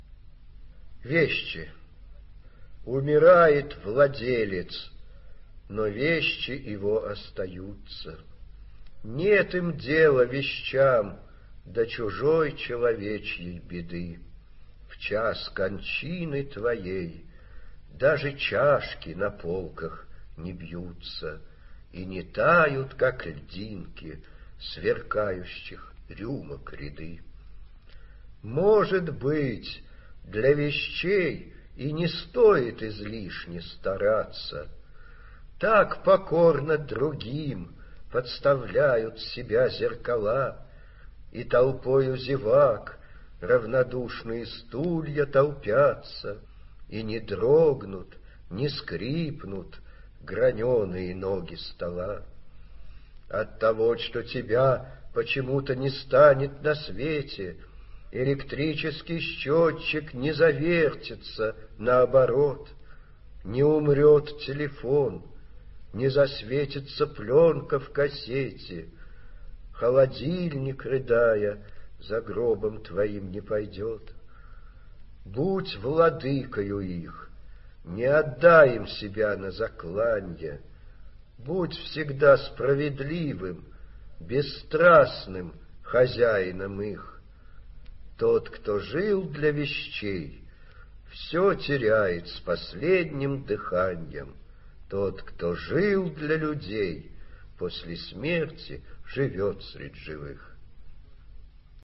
Вадим Шефнер – Вещи (читает автор)
vadim-shefner-veshhi-chitaet-avtor